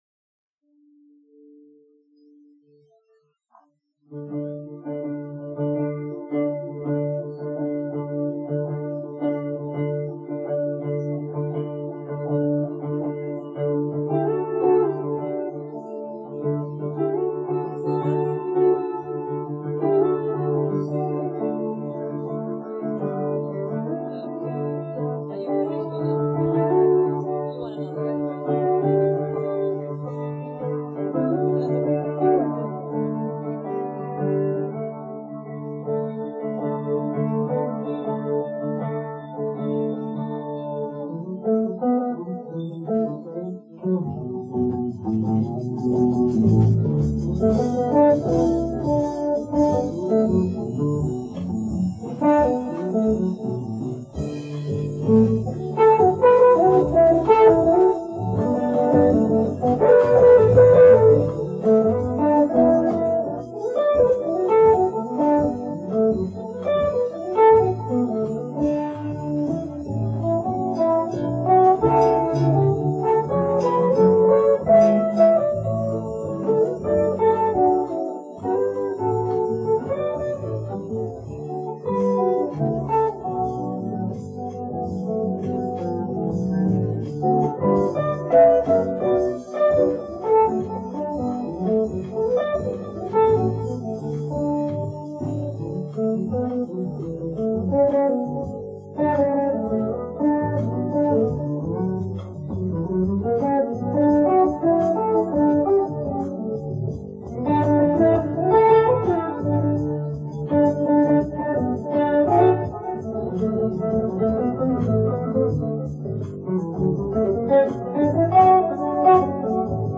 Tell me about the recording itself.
Hiss is gone...might be better-might be worse...